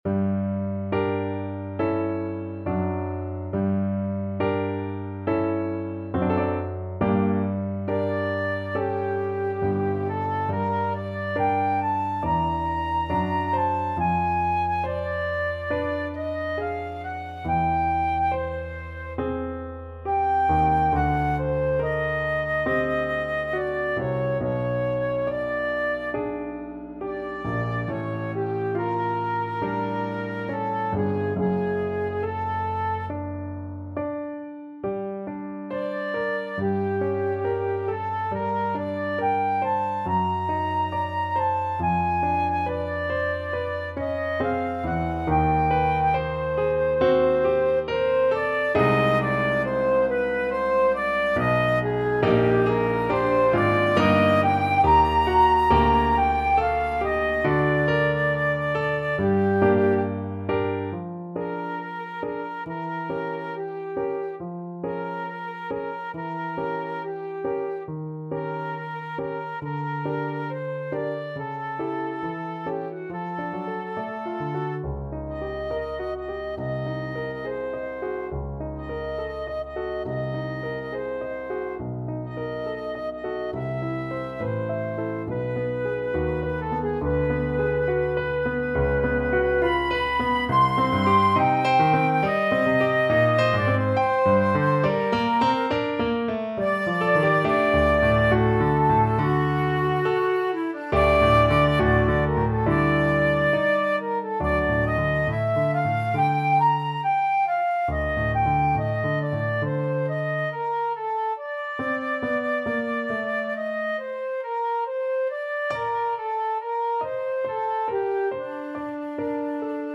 Flute version
4/4 (View more 4/4 Music)
~ = 69 Andante con duolo
Classical (View more Classical Flute Music)